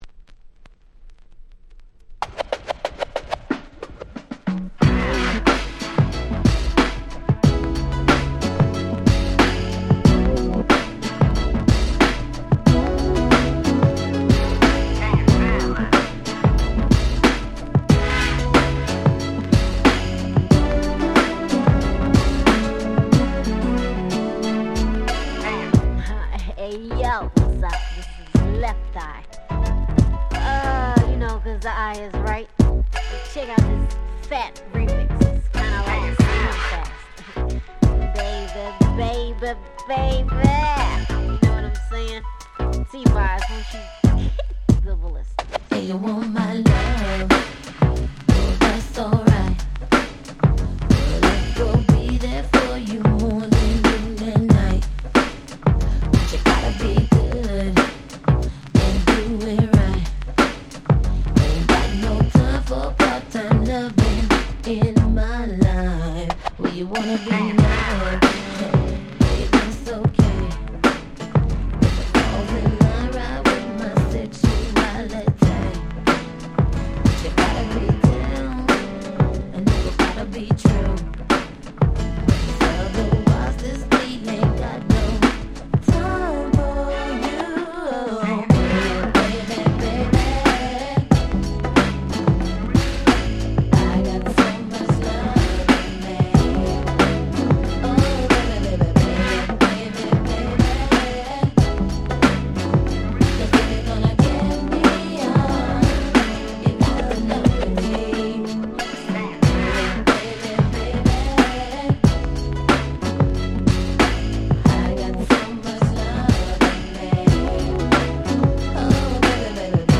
【Media】Vinyl 12'' Single
※一部試聴ファイルは別の盤から録音してございます。
92' Very Nice R&B / New Jack Swing !!